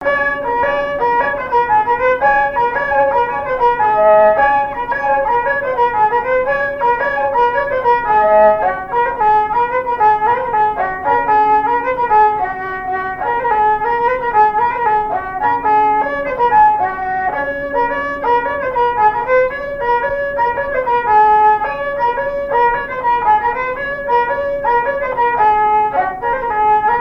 branle : courante, maraîchine
répertoire au violon et à la mandoline
Pièce musicale inédite